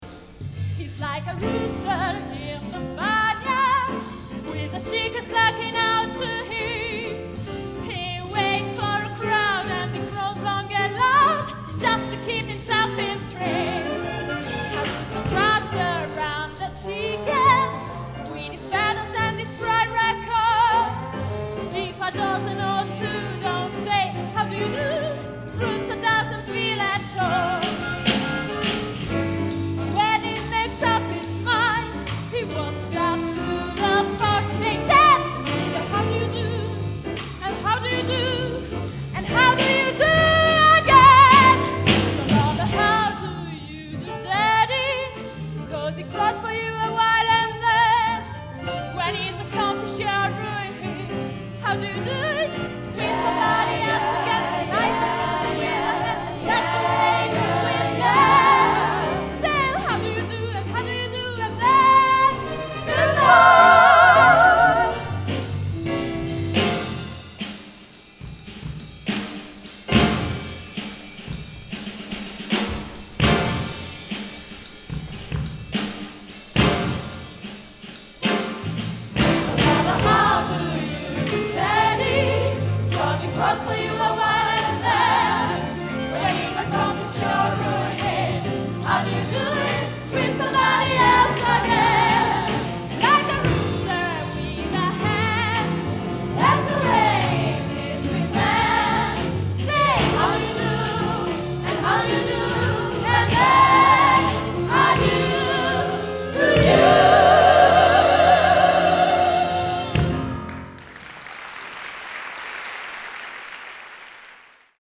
sax contralto
sax tenore
clarinetto
tromba
trombone
chitarra elettrica
pianoforte
basso elettrico
batteria